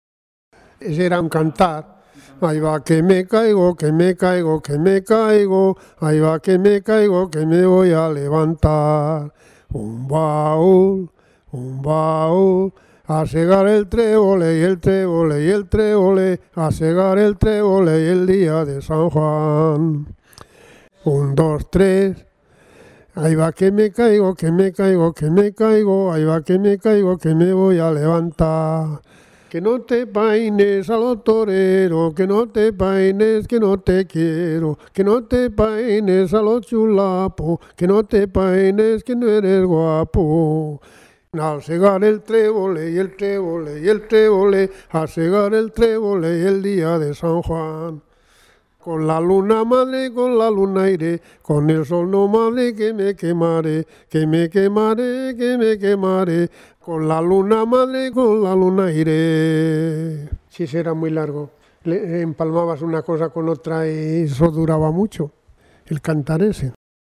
Clasificación: Cancionero
Lugar y fecha de recogida: Logroño, 8 de julio de 2004